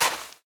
sand3.ogg